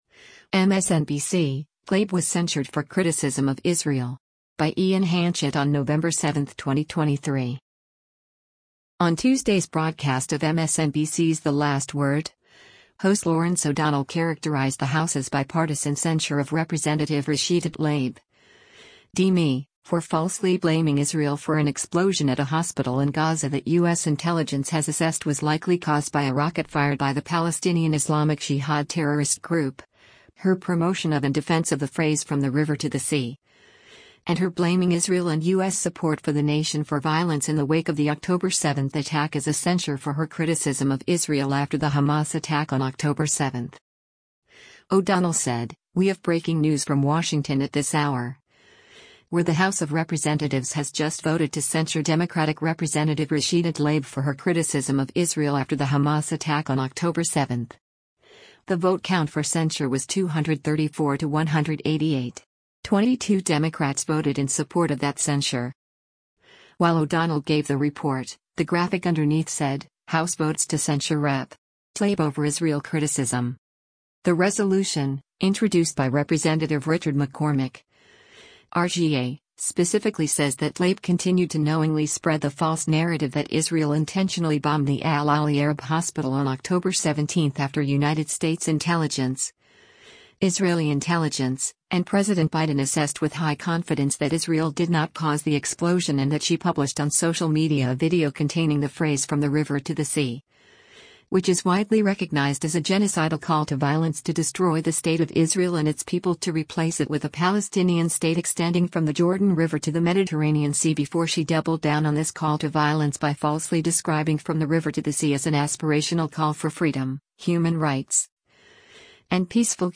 O’Donnell said, “We have breaking news from Washington at this hour, where the House of Representatives has just voted to censure Democratic Rep. Rashida Tlaib for her criticism of Israel after the Hamas attack on October 7. The vote count for censure was 234 to 188. 22 Democrats voted in support of that censure.”